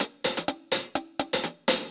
Index of /musicradar/ultimate-hihat-samples/125bpm
UHH_AcoustiHatB_125-05.wav